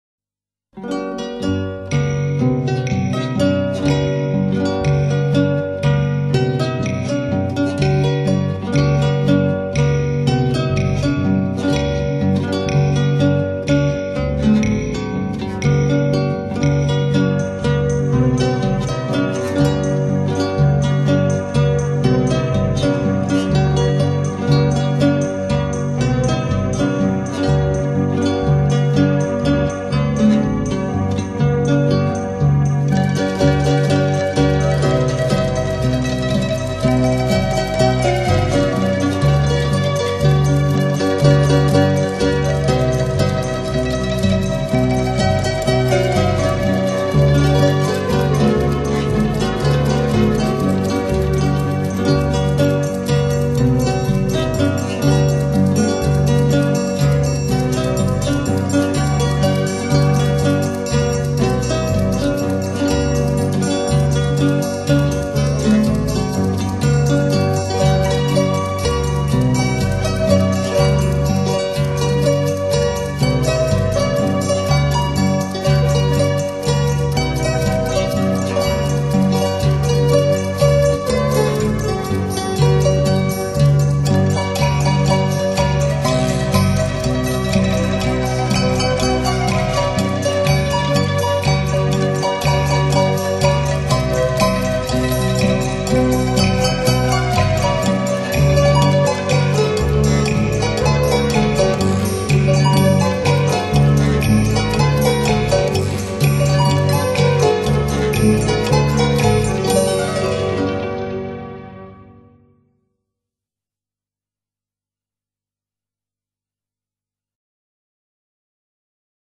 竖琴精品 名家演奏 如诗如梦 不容错过